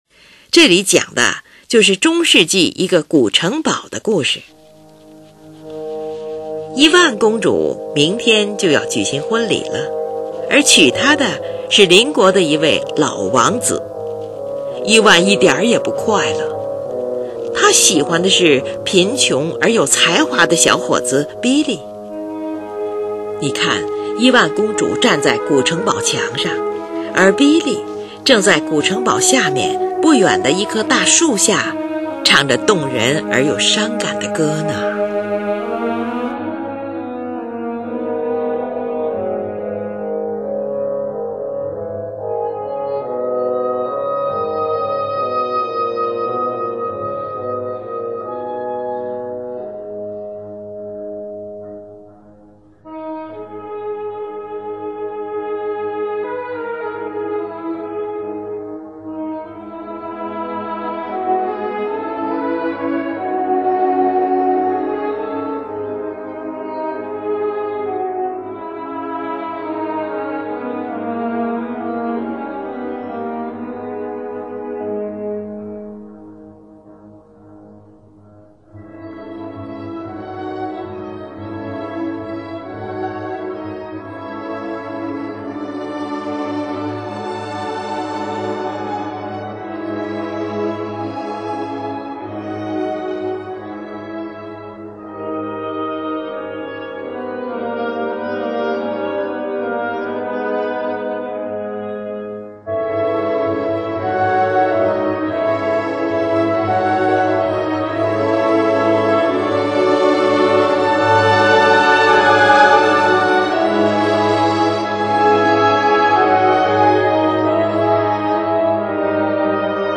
后来有几位作曲家将这首钢琴组曲改编成管弦乐曲
它的音响显得那样高贵、浪漫。
这首乐曲的配器清淡严格，非常完整。